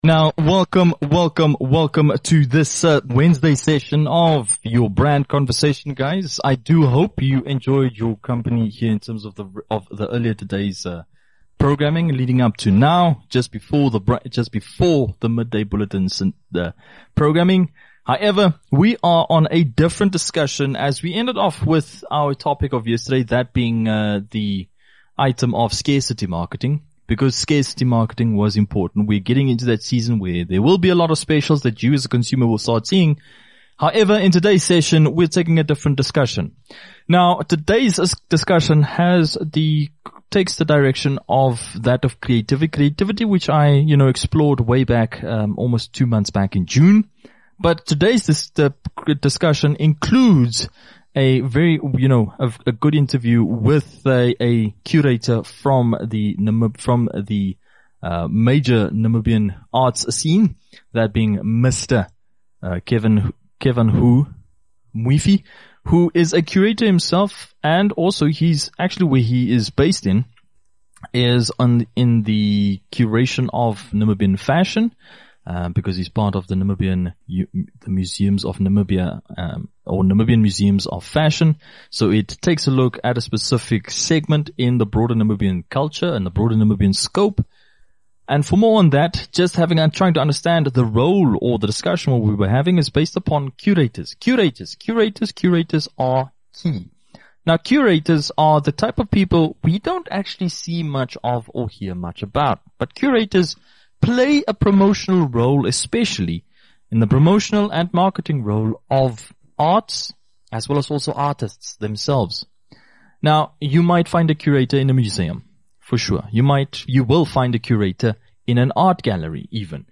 Interview with a Curator